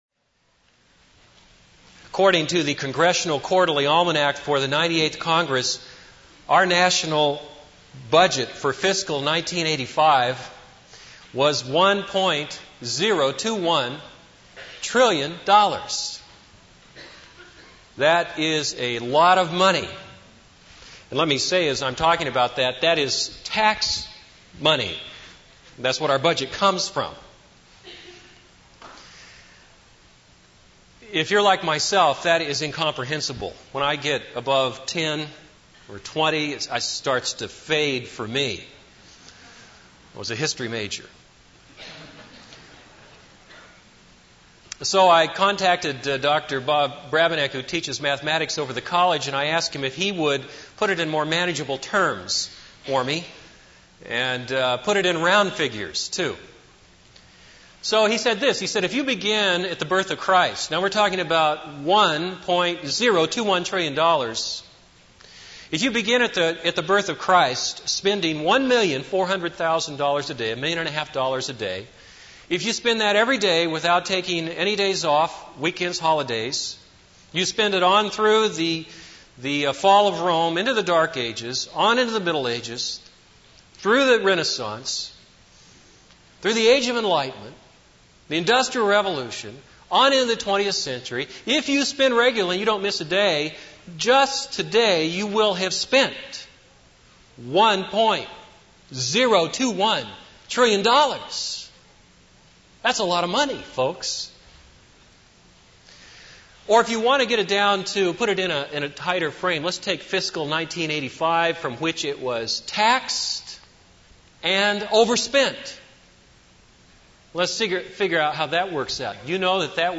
This is a sermon on Mark 2:13-17.